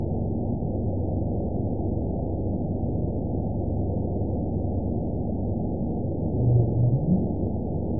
event 914386 date 05/06/22 time 19:44:17 GMT (3 years ago) score 8.59 location TSS-AB02 detected by nrw target species NRW annotations +NRW Spectrogram: Frequency (kHz) vs. Time (s) audio not available .wav